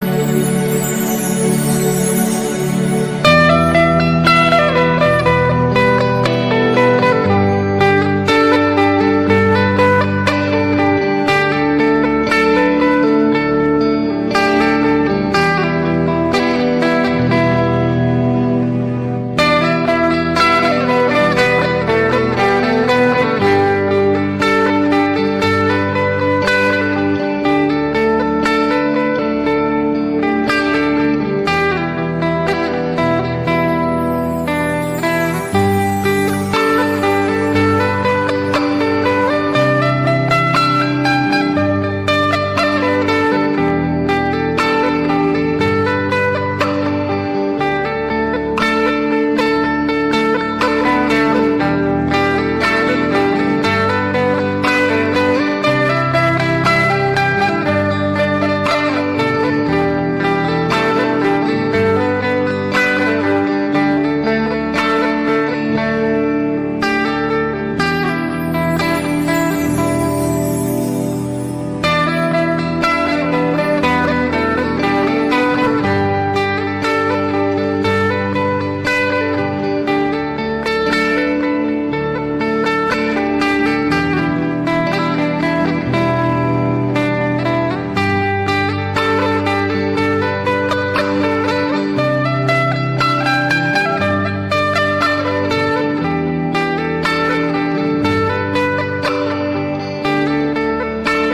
INSTRUMEN MUSIK DAYAK BORNEO